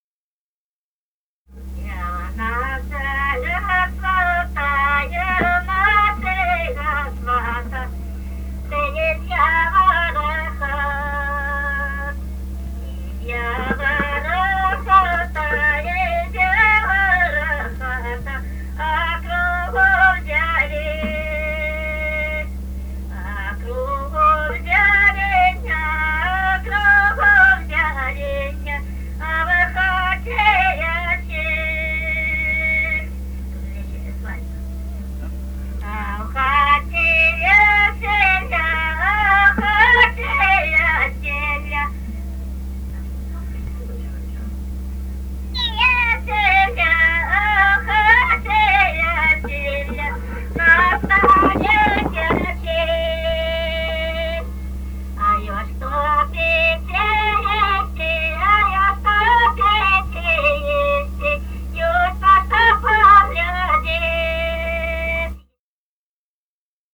Русские народные песни Красноярского края.